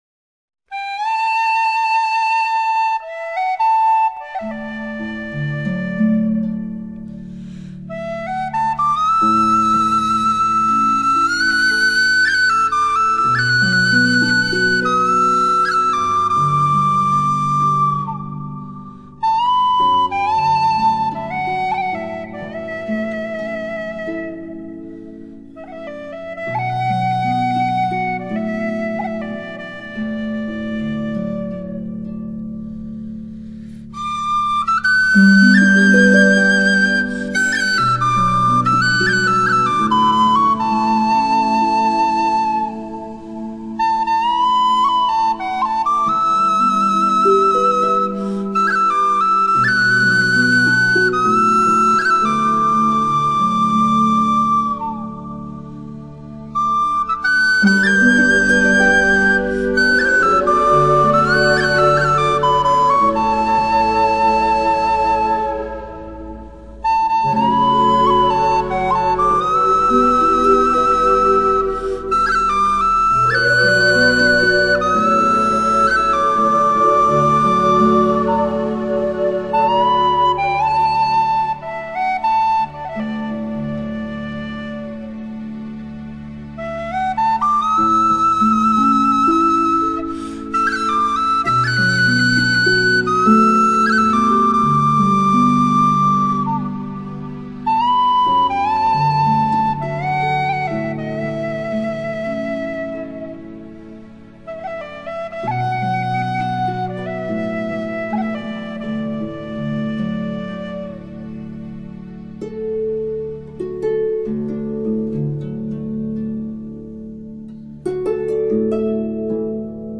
【爱尔兰风笛】The Black Rose 黑玫瑰
爱尔兰风笛跟苏格兰风笛在演奏跟音色上有区别，苏格兰风笛是用嘴吹气给气囊鼓风的，而爱尔兰风笛靠肘部的鼓风器给气囊鼓风，最后两种乐器都是由气囊带动簧管发声。